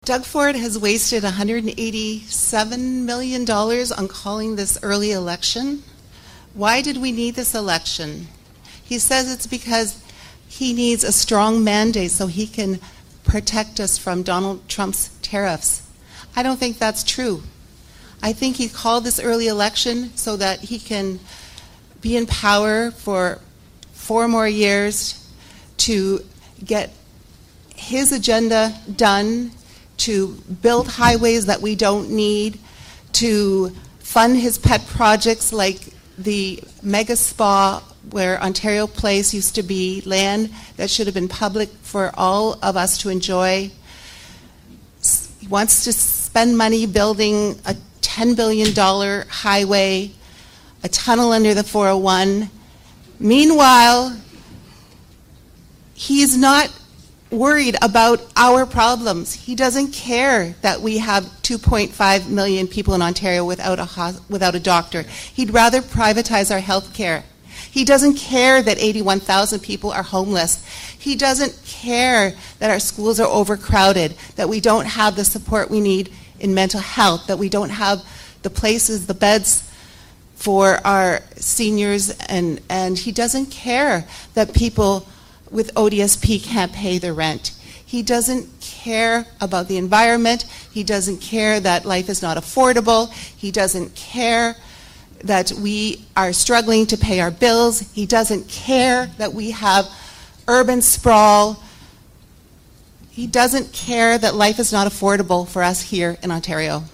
It seemed fitting that, with only one debate featuring a majority of the candidates being held, that we highlighted their closing statements from that night on the final day of the election campaign.